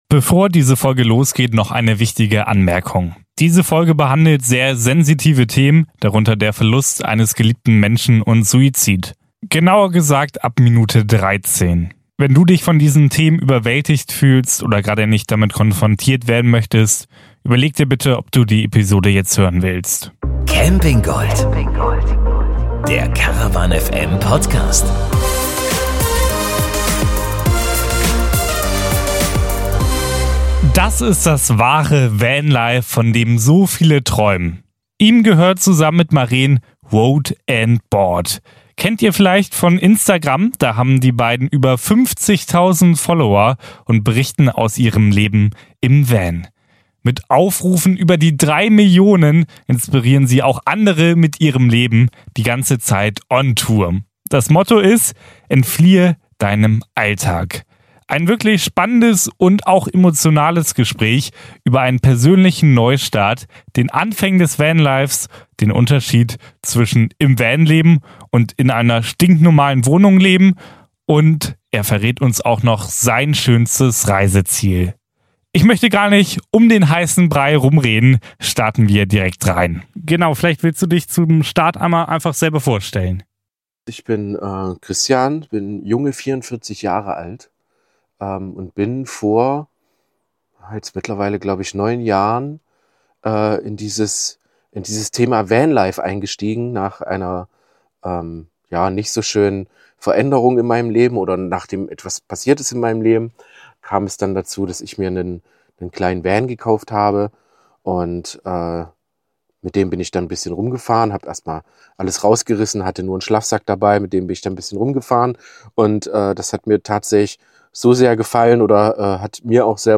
Ein Gespräch, das unter die Haut geht, aber auch Hoffnung und Inspiration liefert – für einen Neuanfang im Leben, das Abenteuer Vanlife und die wahre Bedeutung des Unt...